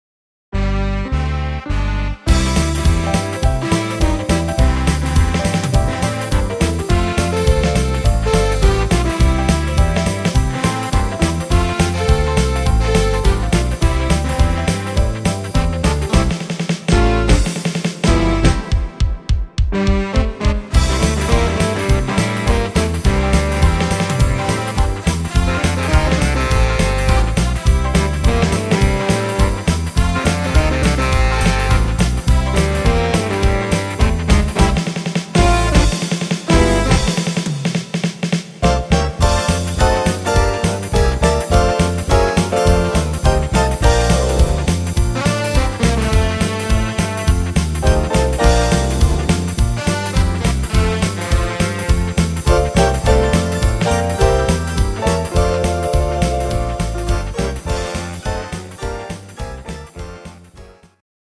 Instr. Big Band